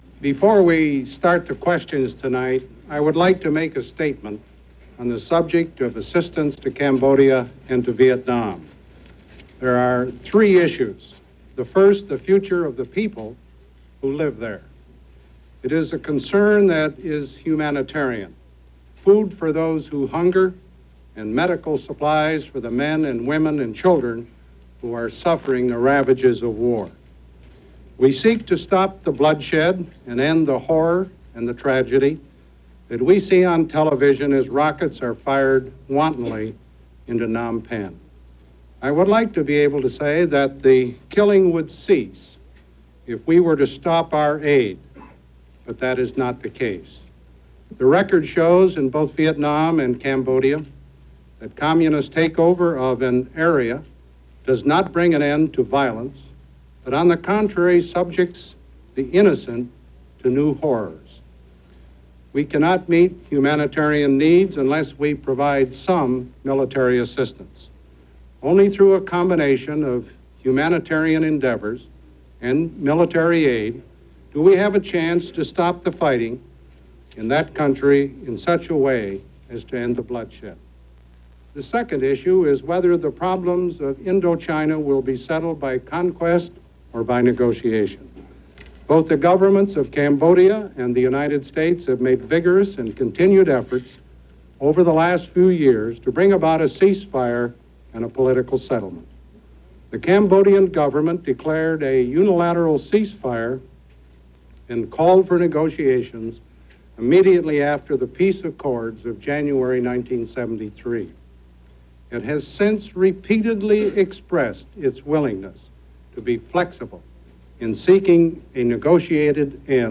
March 6,1975 - The Situation In Cambodia - Pres. Ford's Press Conference -
March 6, 1975 – As the situation in Cambodia grew dimmer and as Vietnam was getting ready to capitulate, President Ford held his 10th Press Conference since taking office – he began with a statement: